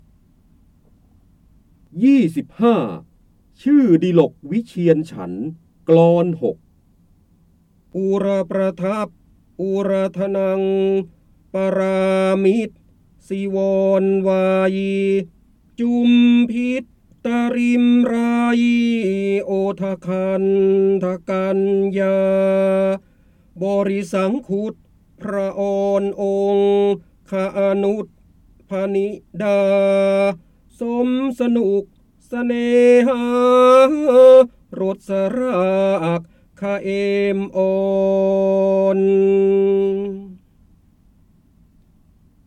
เสียงบรรยายจากหนังสือ จินดามณี (พระโหราธิบดี) ๒๕ ชื่อวิเชียรดิลกฉันทกลอน๖
คำสำคัญ : พระเจ้าบรมโกศ, จินดามณี, ร้อยแก้ว, พระโหราธิบดี, ร้อยกรอง, การอ่านออกเสียง
ลักษณะของสื่อ :   คลิปการเรียนรู้, คลิปเสียง